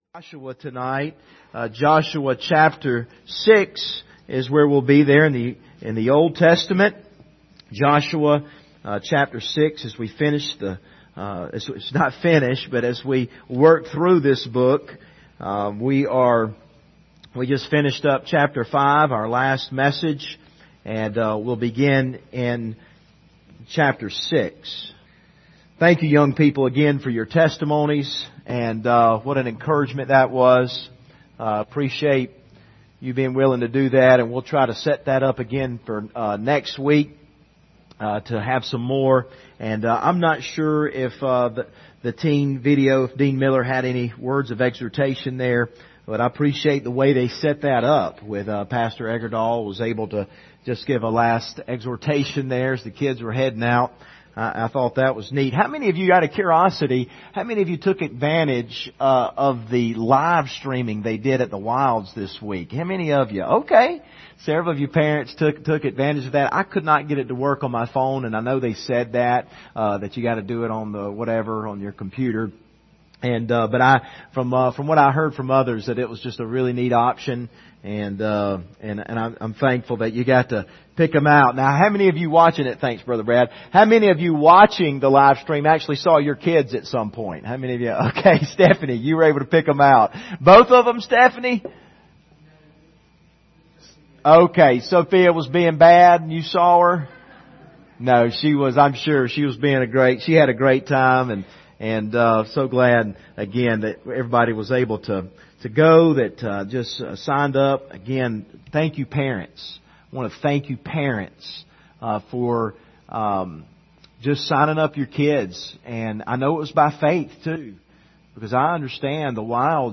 Faith is the Victory Passage: Joshua 6:1-10 Service Type: Sunday Evening « Summer Camp Testimonies Can God Be Trusted?